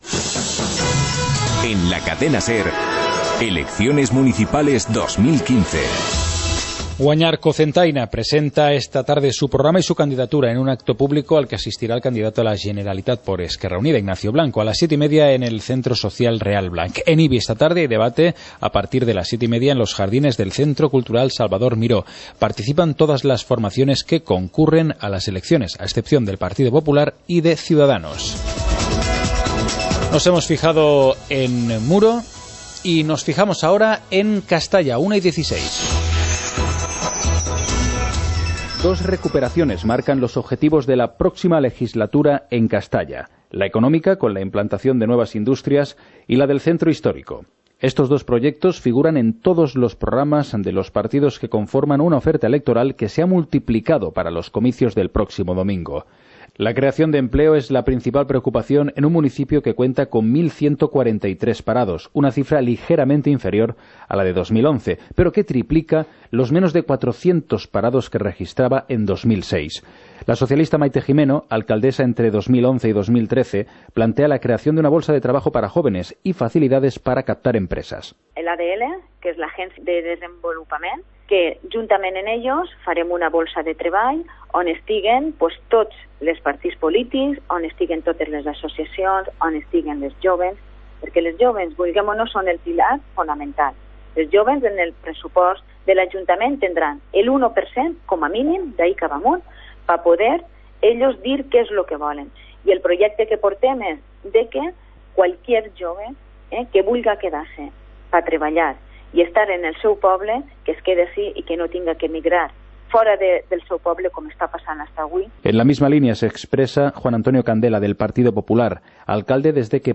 Informativo comarcal - miércoles, 20 de mayo de 2015